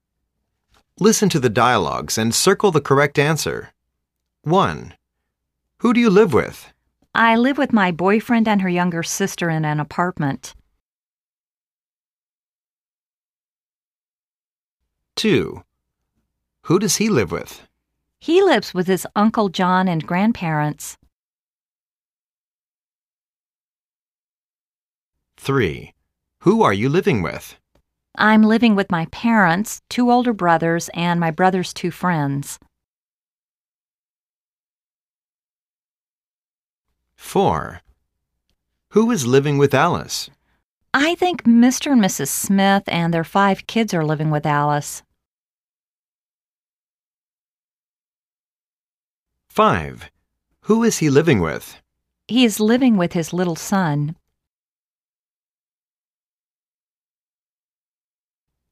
英语对话1